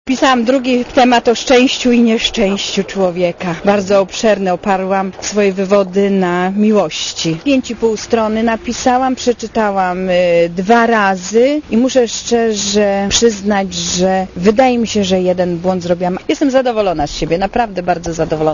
Mówi Renata Beger